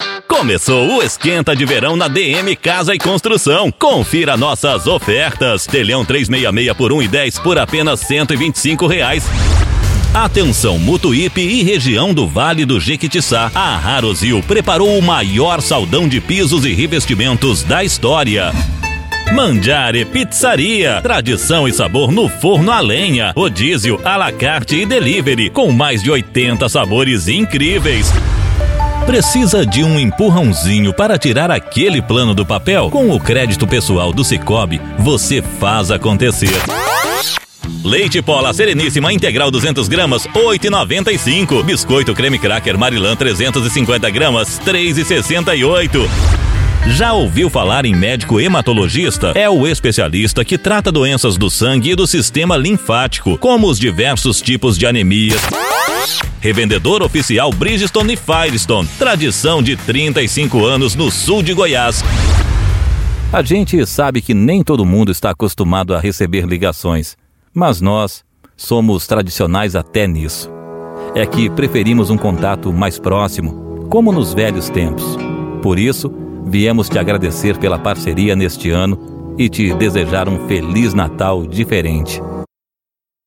Spot Comercial
Vinhetas
VT Comercial
Animada